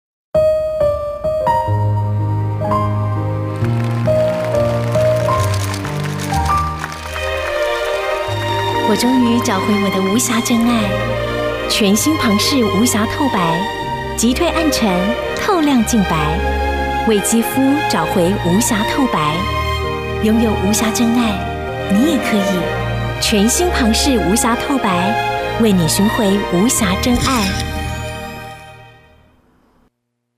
女性配音員
微電影—甜美年輕：台灣旁氏
✔ 聲線沉穩柔和、極具親和力，適合廣告、政府宣導、公部門簡報與品牌行銷影片。
微電影—甜美年輕：台灣旁氏.mp3